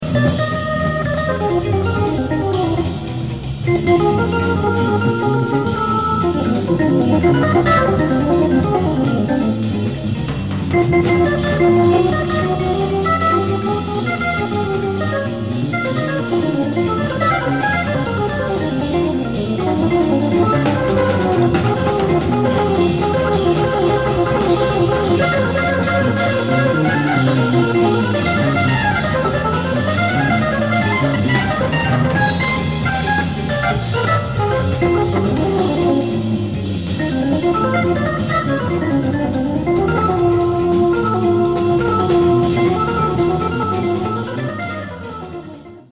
on entend une reverb particuliére sur l'orgue !
C'est vrai que la reverb est étrange.
guitare électrique
batterie